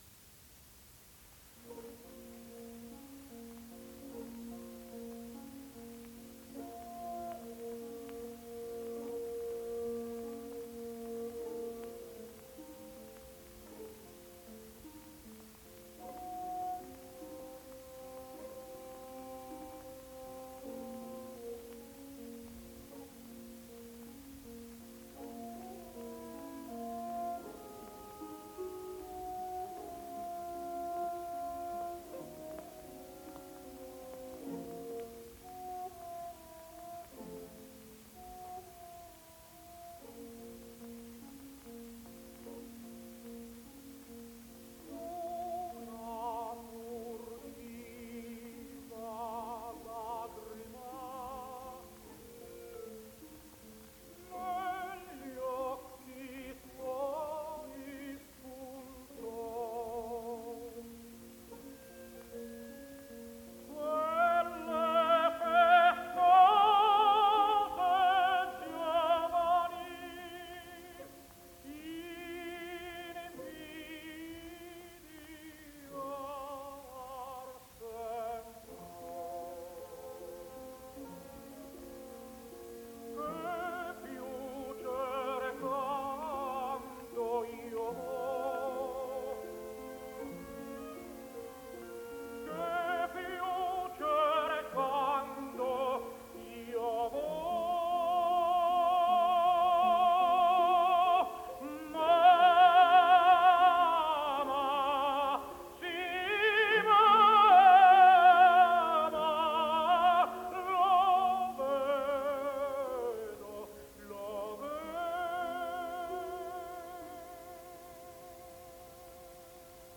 Tenore TITO SCHIPA “L’emozione”